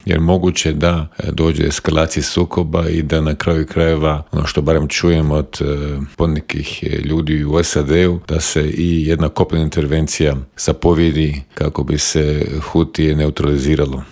Bivšeg ministra vanjskih i europskih poslova Miru Kovača u Intervjuu Media servisa upitali smo - je li ga iznenadio postupak SAD-a?